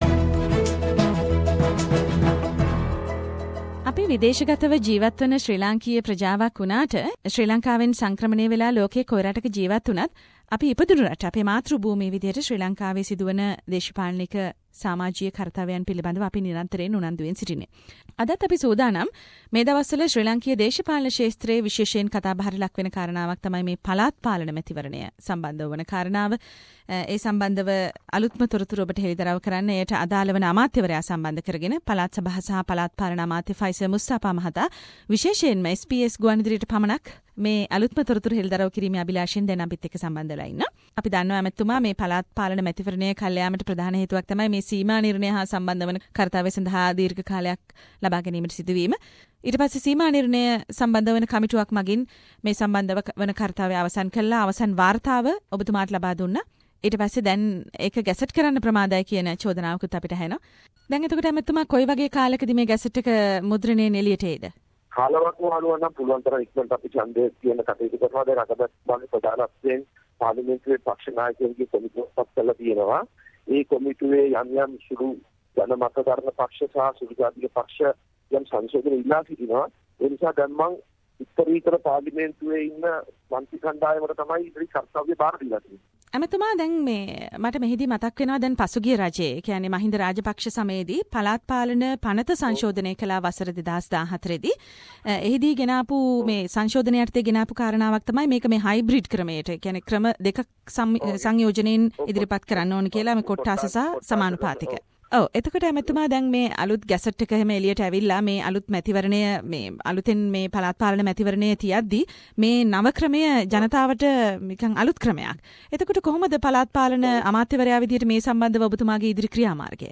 SBS Sinhalese has done an exclusive interview with the Minister of Provincial Councils and Local Government of Sri Lanka Mr: Faiszer Musthapha about the debatable situation of ongoing postponing of local elections in Sri Lanka.